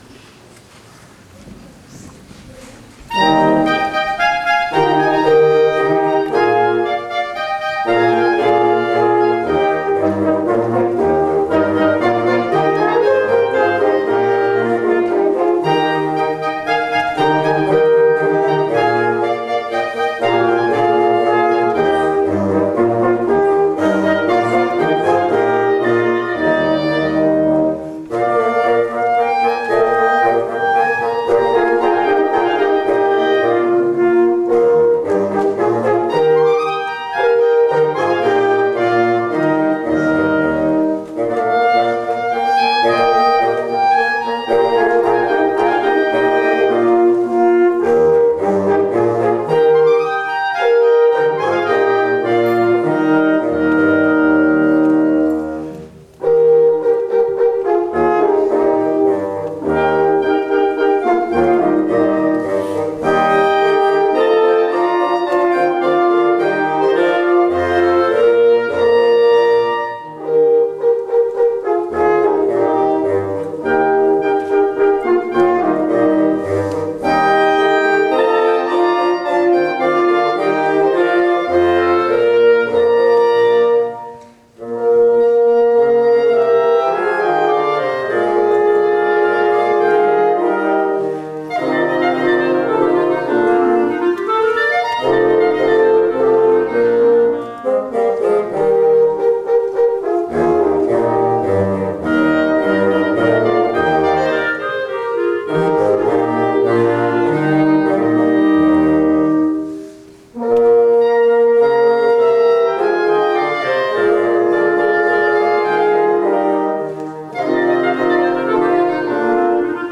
On Sunday, September 9, 2018, the Lititz Moravian Collegium Musicum presented an inaugural concert in front of a full house.
Trumpet, Flute, Clarinets, Bassoons, Horns